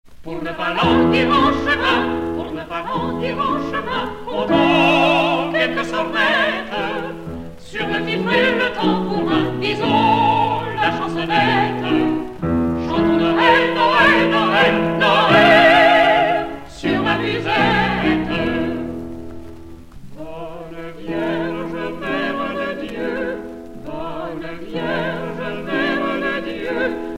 Noël, Nativité
Genre strophique